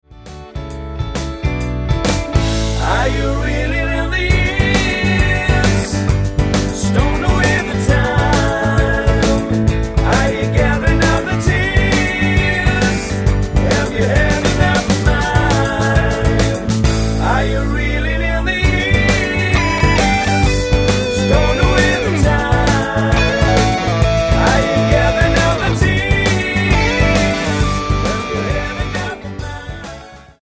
Tonart:A mit Chor